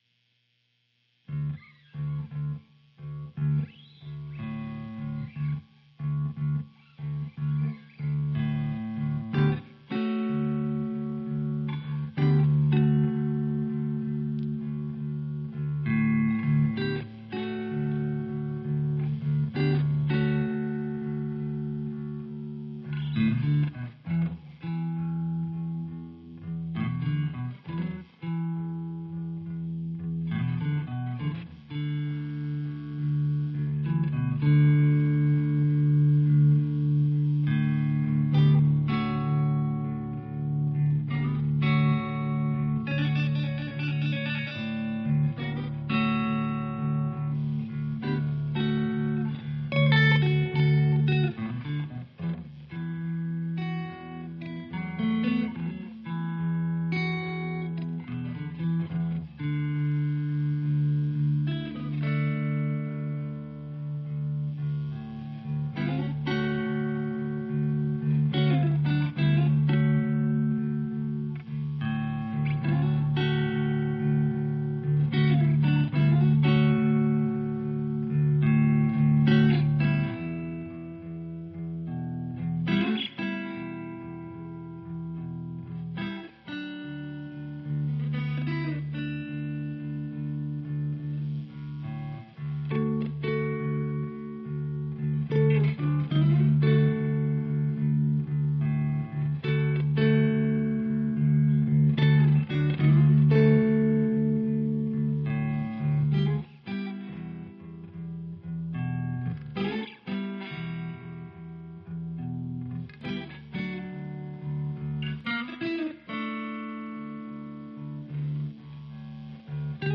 In mono and lofi because of file size.